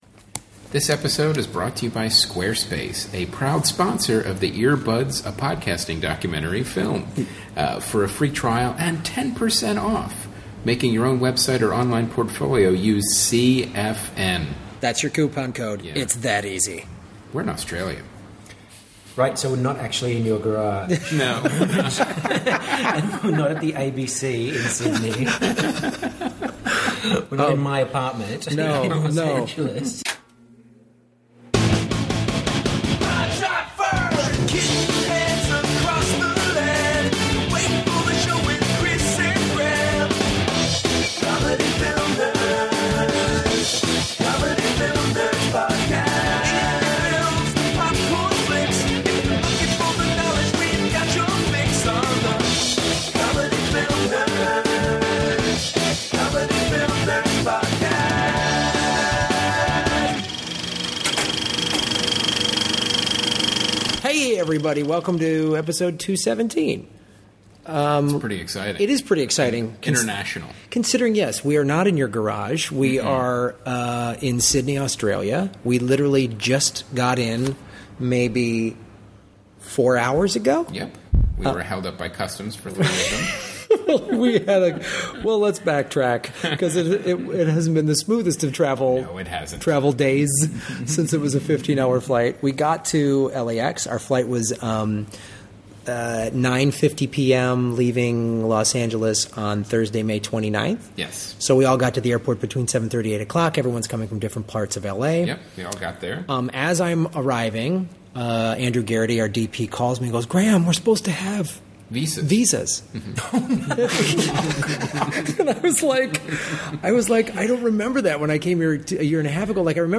It is the first ever CFN ep recorded on foreign soil so enjoy it!